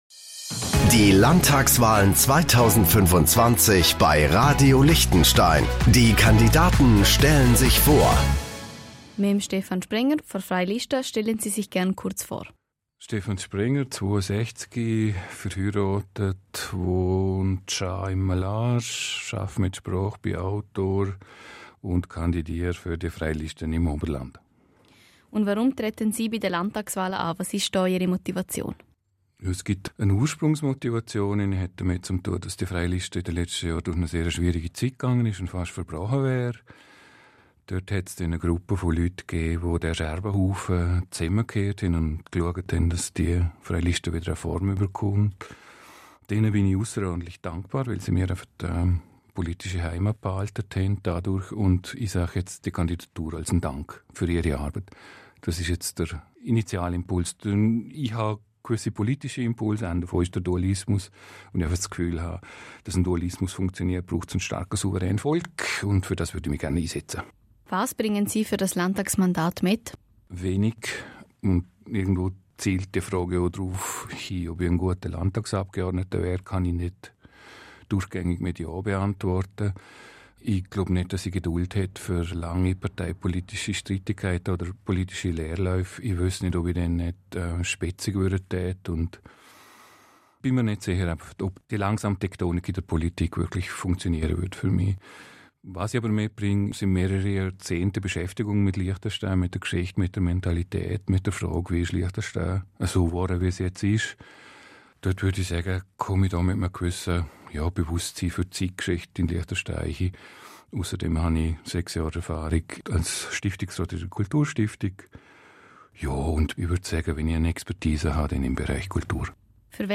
Landtagskandidat